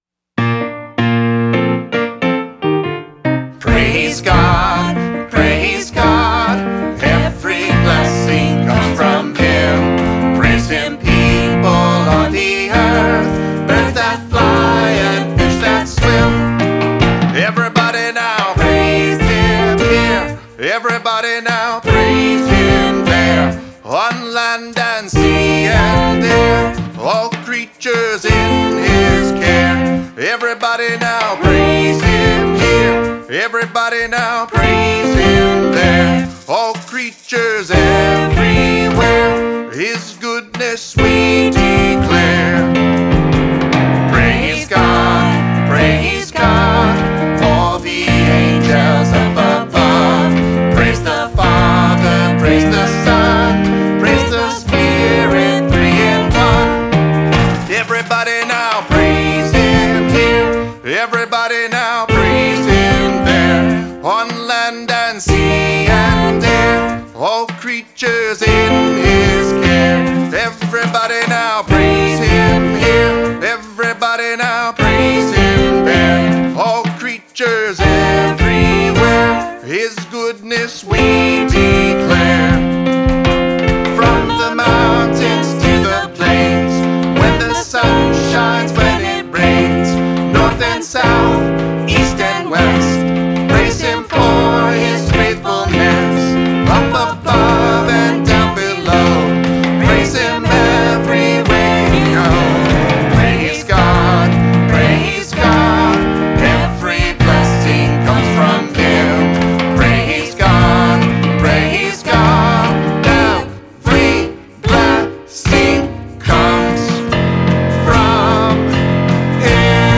vocals, guitar, banjo, harmonica
upright bass
percussion
piano, trumpet, flugelhorn